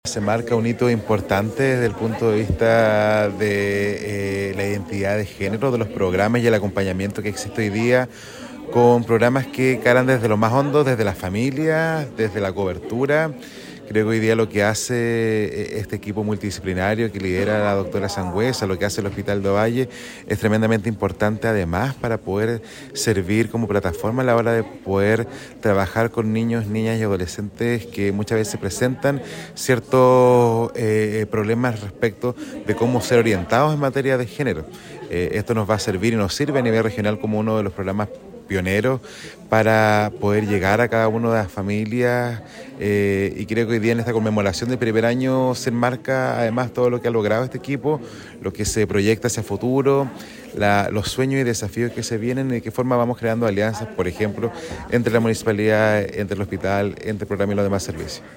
A la ceremonia asistió el alcalde de Ovalle, Jonathan Acuña, quien comentó que
Jonathan-Acuna-R.-Alcalde-de-Ovalle.mp3